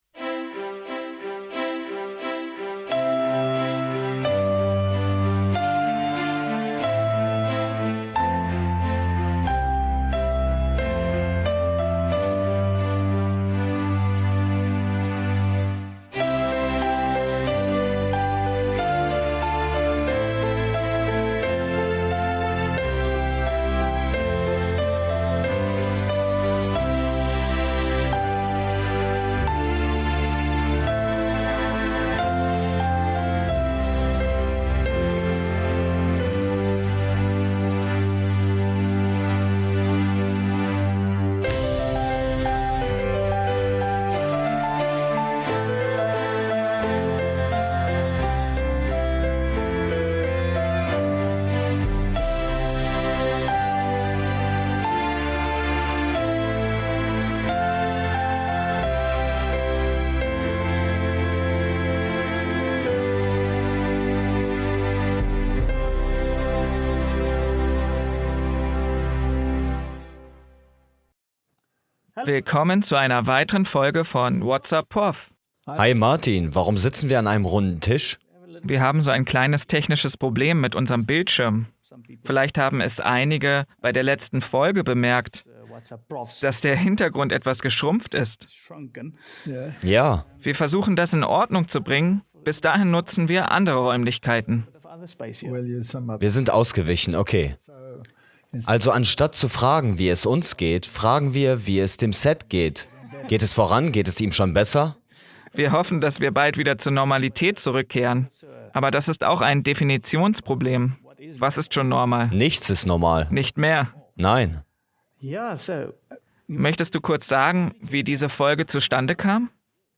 (Voice Over)